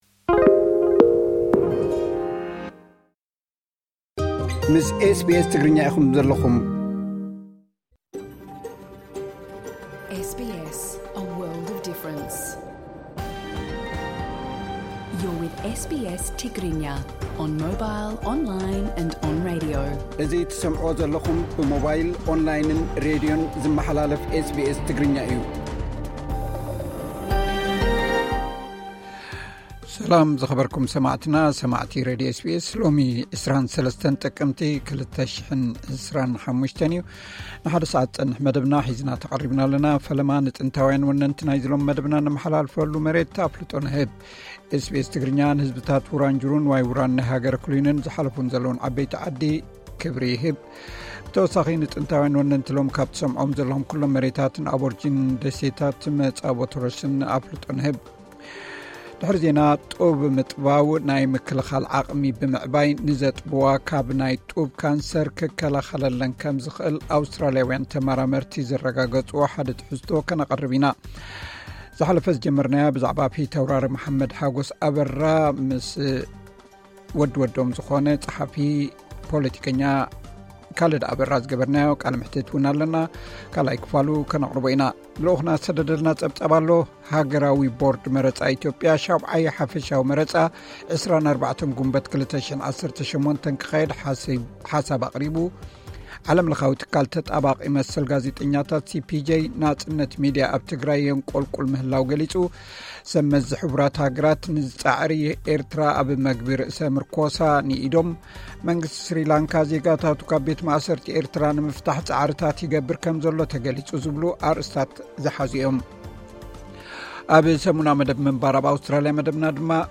ቀጥታ ምሉእ ትሕዝቶ ኤስ ቢ ኤስ ትግርኛ (23 ጥቅምቲ 2025)